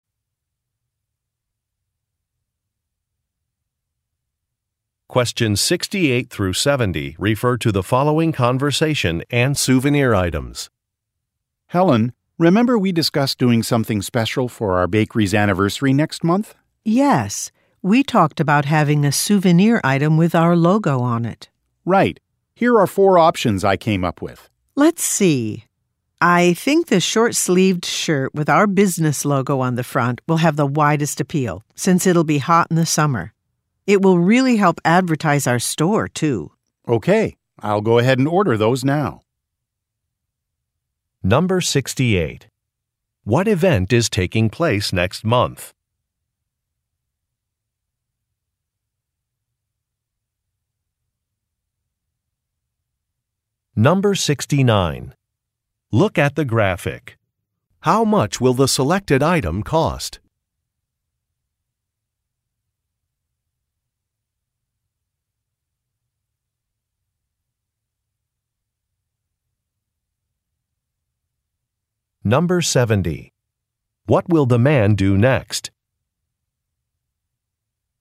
Question 68 - 70 refer to following conversation: